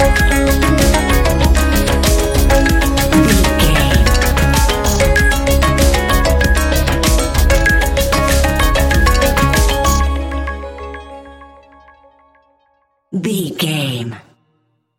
Ionian/Major
C♯
techno
trance
synths
chillwave